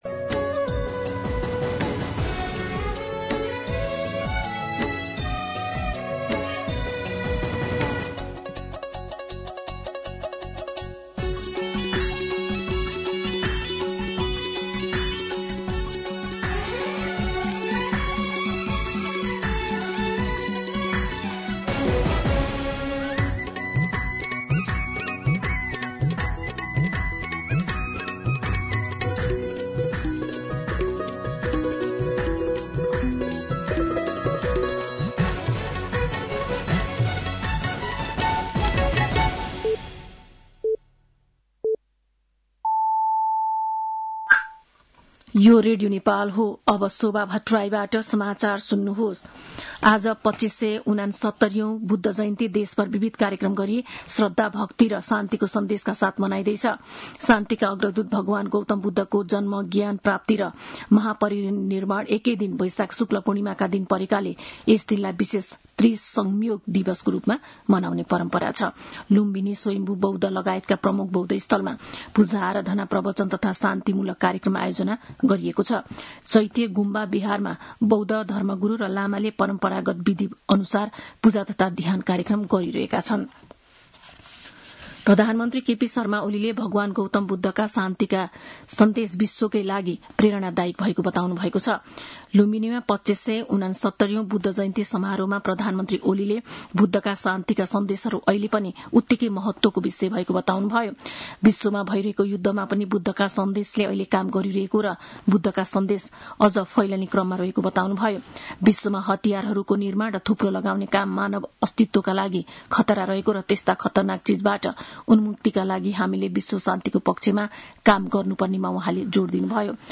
साँझ ५ बजेको नेपाली समाचार : २९ वैशाख , २०८२
5.-pm-nepali-news-2.mp3